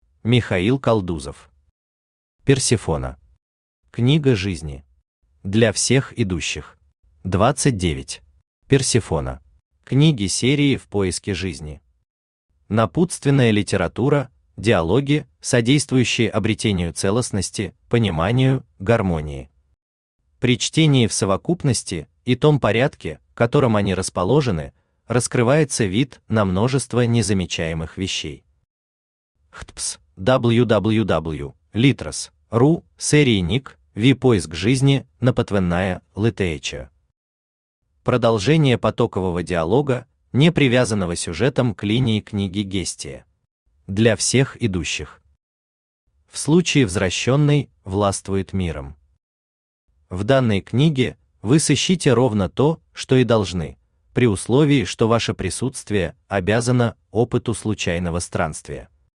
Аудиокнига Персефона. Книга жизни. Для всех идущих | Библиотека аудиокниг
Для всех идущих Автор Михаил Константинович Калдузов Читает аудиокнигу Авточтец ЛитРес.